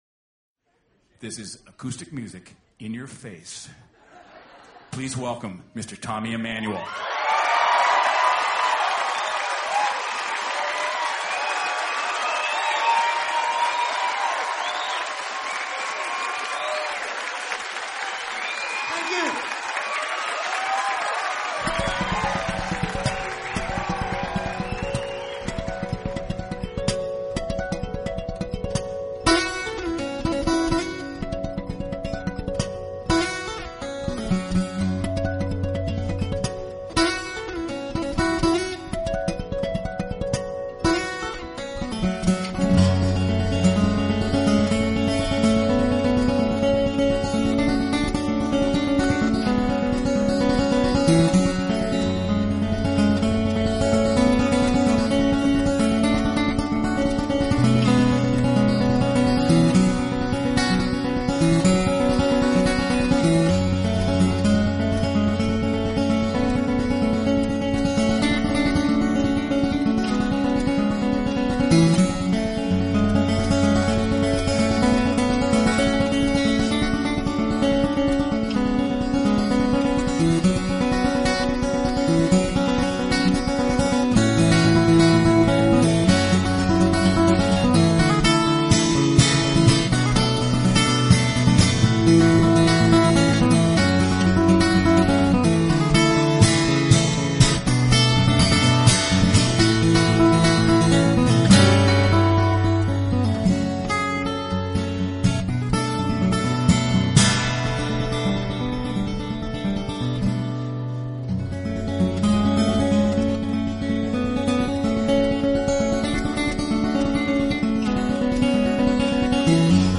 音乐类型：Instrumental